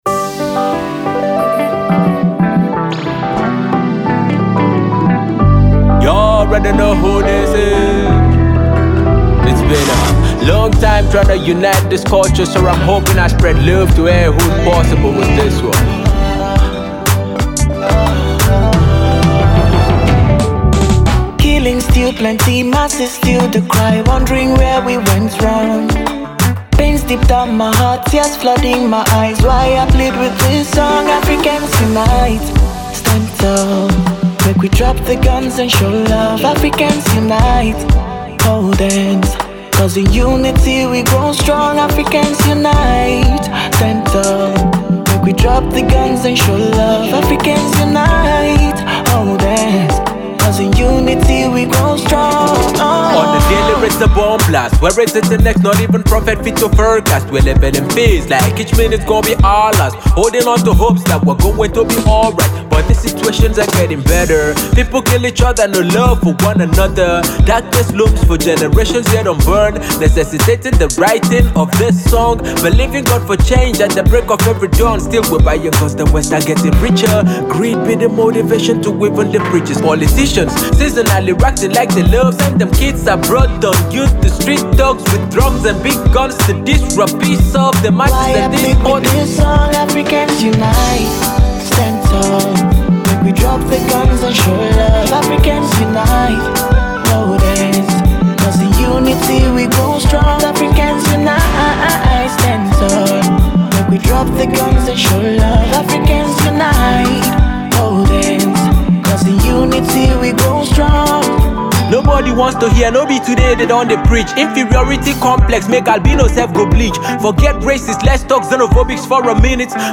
thought provoking musical piece